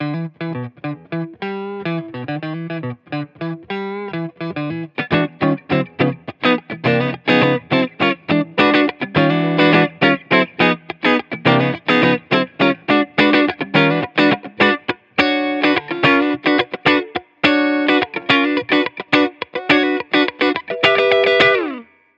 Электрогитара FENDER SQUIER AFFINITY TELECASTER MN BLACK